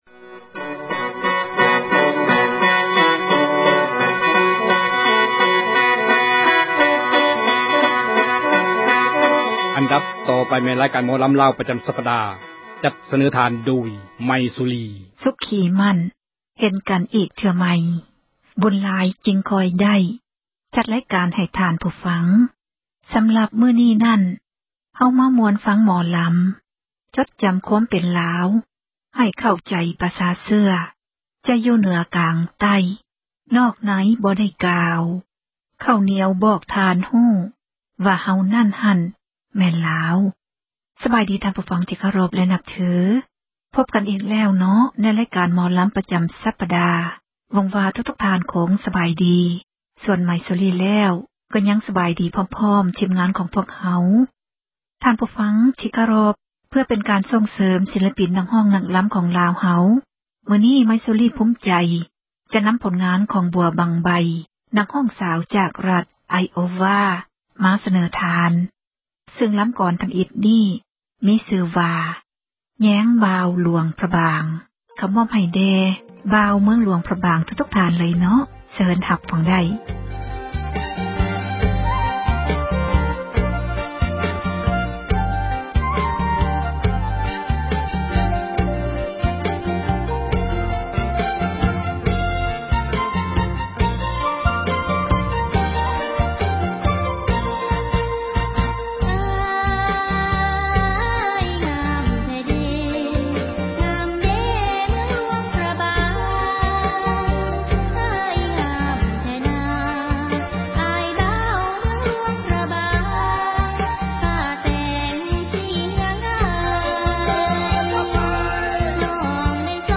ຣາຍການ ໝໍລໍາ ແຕ່ເໜືອ ຕລອດໃຕ້ ຈັດມາສເນີທ່ານ